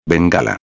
Em espanhol a letra "b (be)" e a letra "v (uve)" têm o mesmo som.
Preste atenção no som das letras "B" e "V".